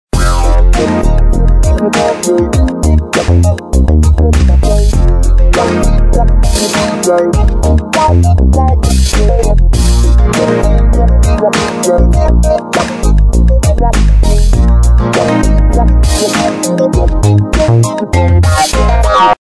Tags: best ringtones free soundboard